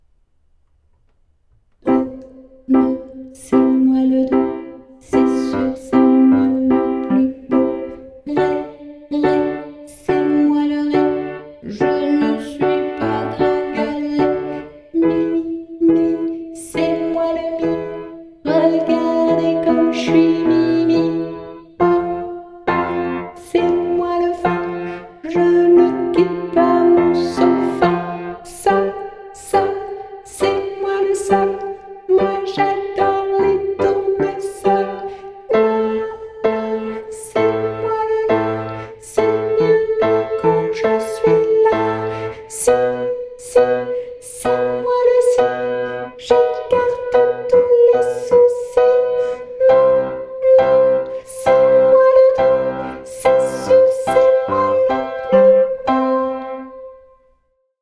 un conte musical pour enfants